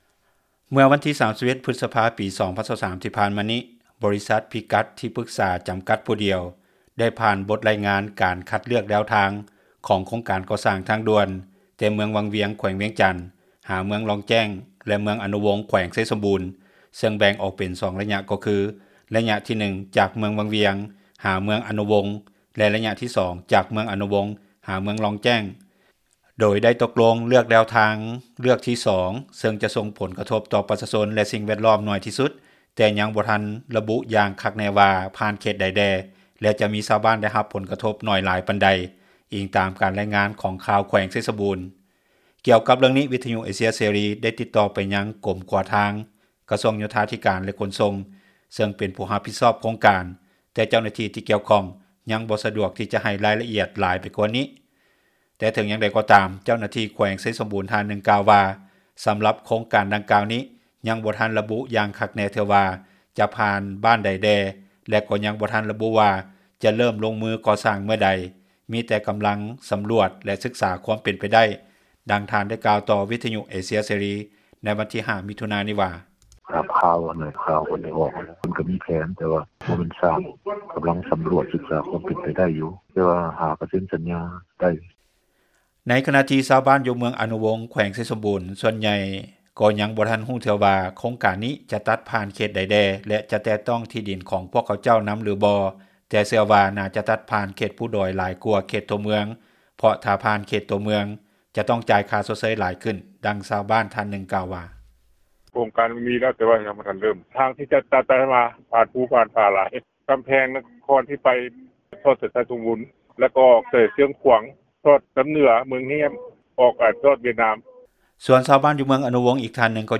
ດັ່ງຊາວບ້ານ ທ່ານນຶ່ງກ່າວວ່າ:
ດັ່ງຊາວບ້ານ ອີກທ່ານນຶ່ງກ່າວວ່າ: